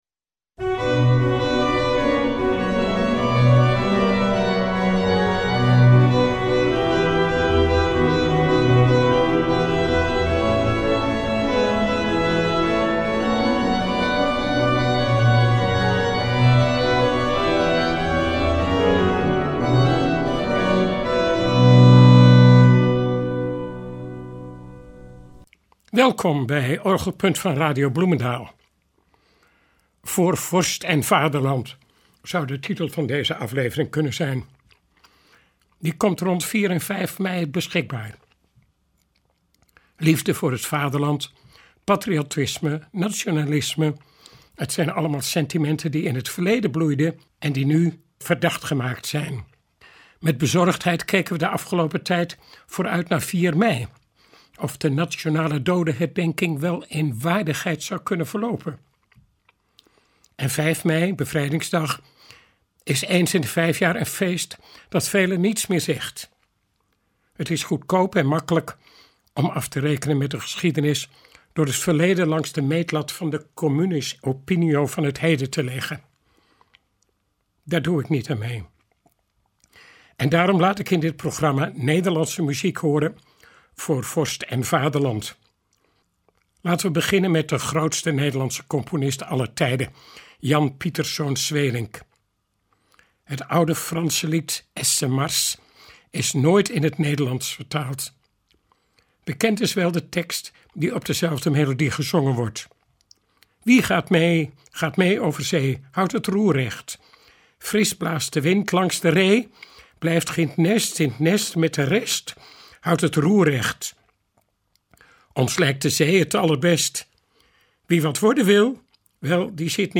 We horen daarin de euforie van een bevrijd land en het elan van een nieuwe tijd. Een latere generatie organisten en componisten ontsluit daarna nieuwe wegen, verrassende klanken.